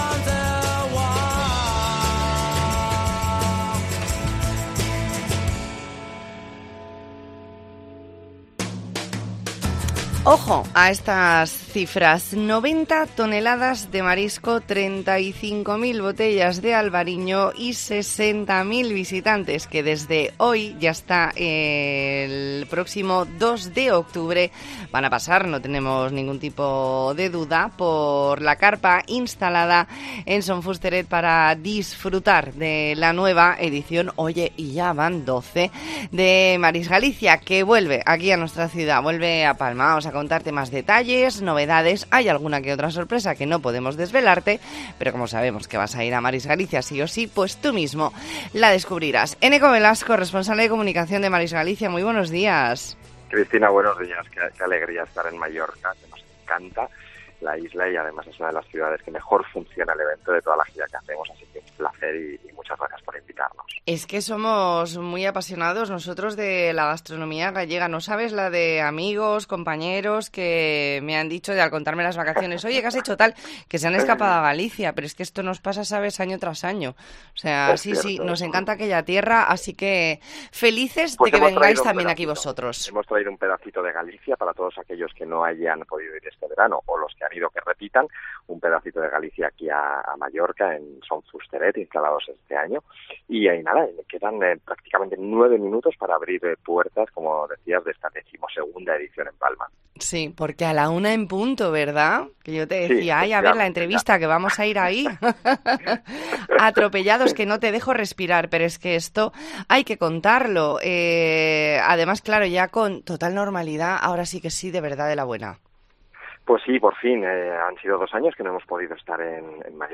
ntrevista en La Mañana en COPE Más Mallorca, viernes 9 de septiembre de 2022.